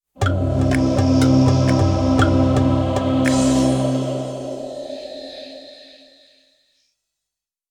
I suoni sono stati ricavati tutti da installazioni di software originale proveniente dalla mia collezione privata di programmi antichi, installazioni eseguite su macchine virtuali "VirtualBox".
Apertura desktop GNOME
Trionfale avvio dal sapore africano.
desktop-login.wav